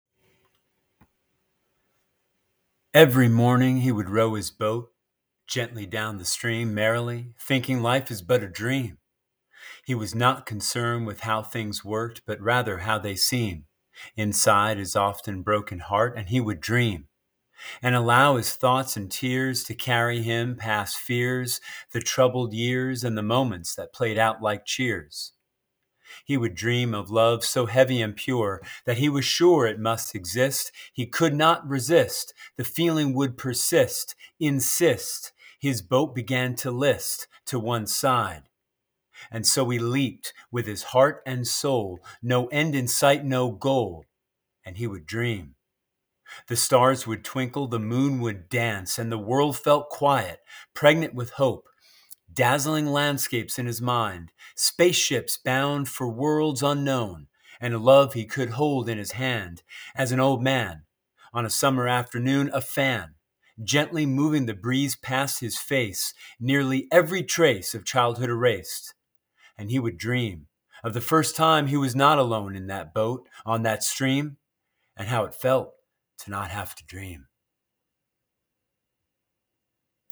by | Feb 17, 2023 | Spoken Poetry